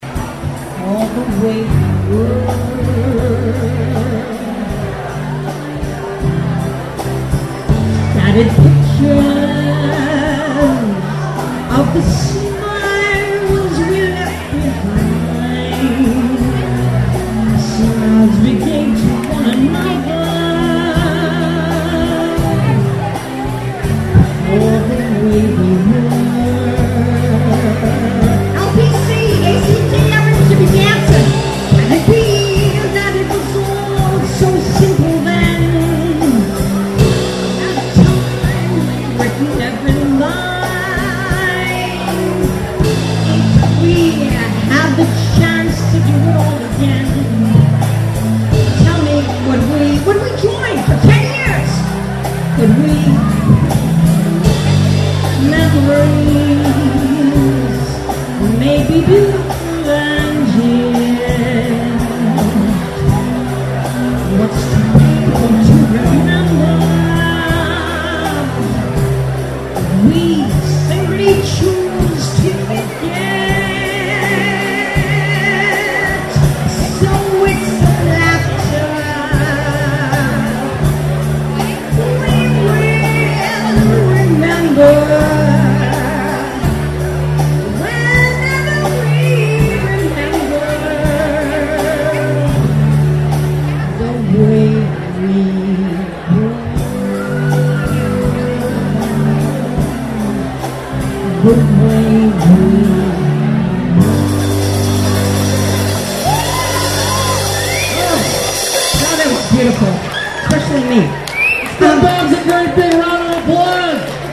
made a guest appearance at our opening reception party.